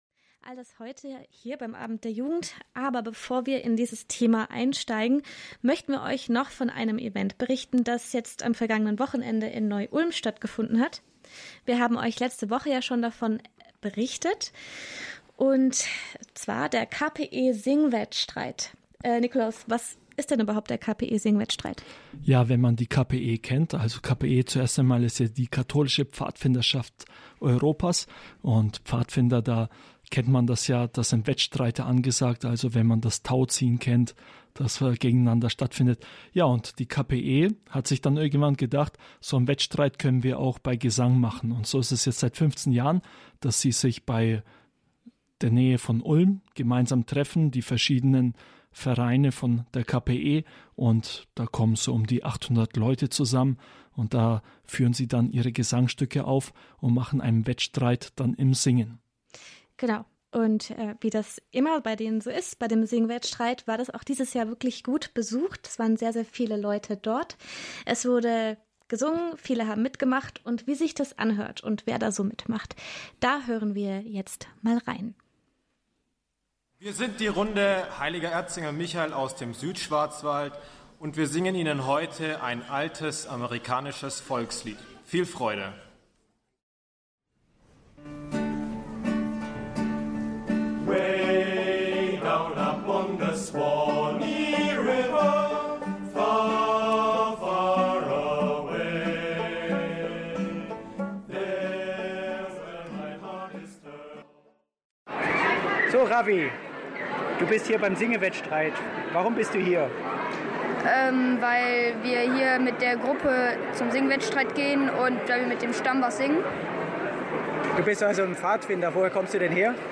Hier ein Bericht auf Radio Horeb über den KPE-Singewettstreit.